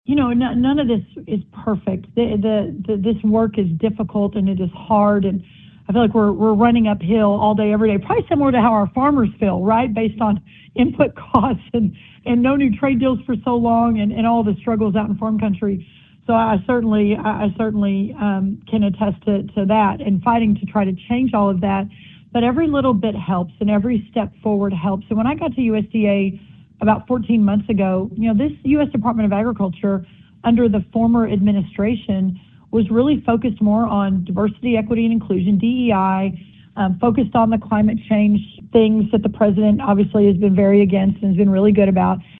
In an interview with the Iowa Agribusiness Radio Network, U.S. Agriculture Secretary Brooke Rollins said several provisions in the law continue to influence decisions tied to succession planning, capital purchases, and rural investment.